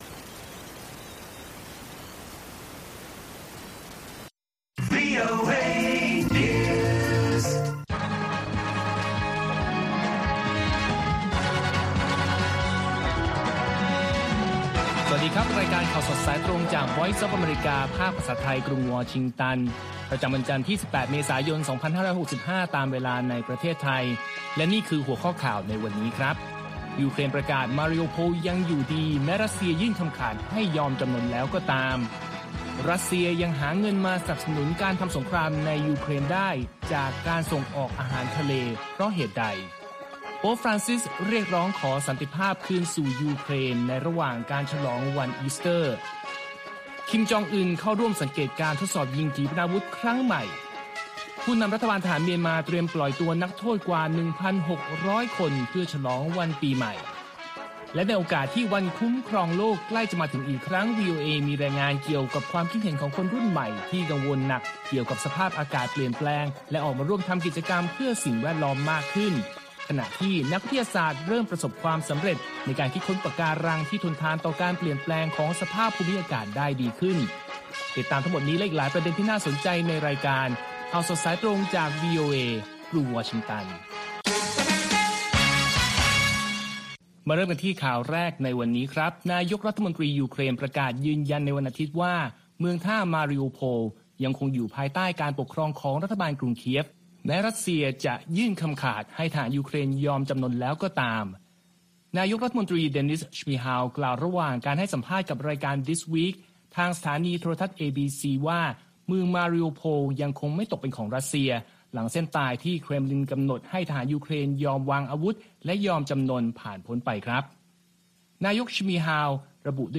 ข่าวสดสายตรงจากวีโอเอ ภาคภาษาไทย 6:30 – 7:00 น. ประจำวันจันทร์ที่ 18 เมษายน 2565 ตามเวลาในประเทศไทย